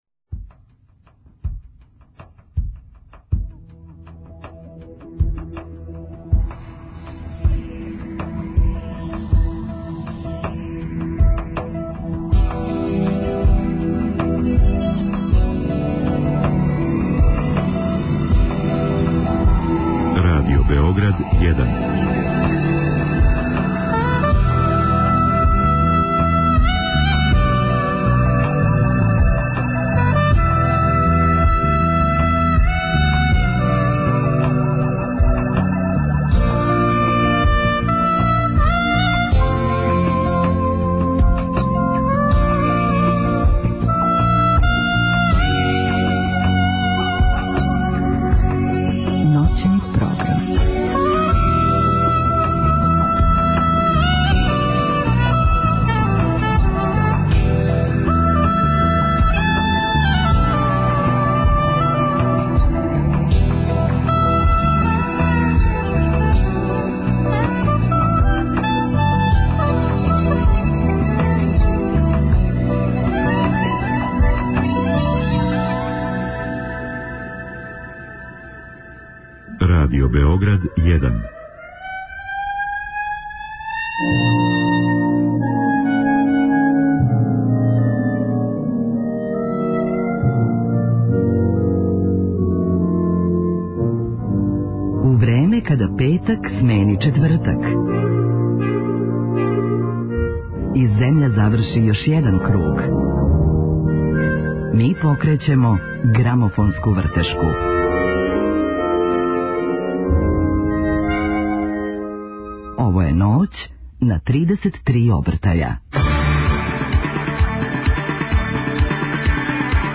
На репертоару су плоче које су освојиле Греми награду за најбољи албум године.